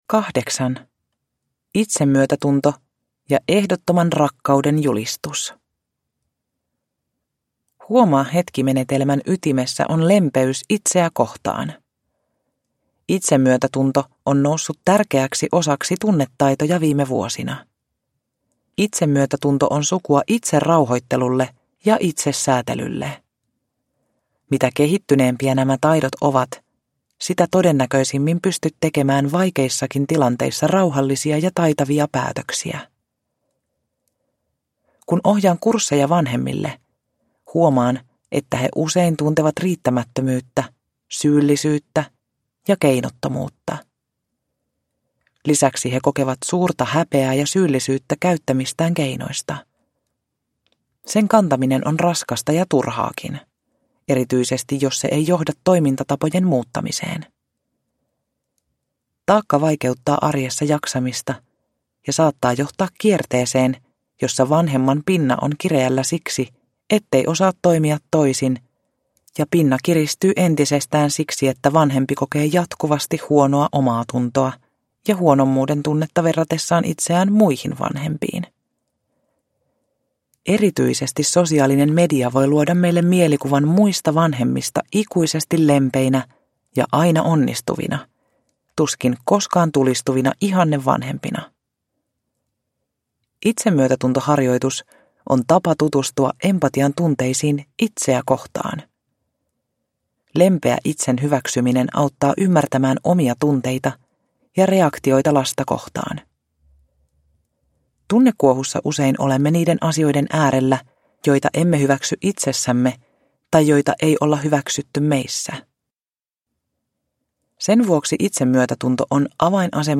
Huomaa hetki perheessä – Ljudbok – Laddas ner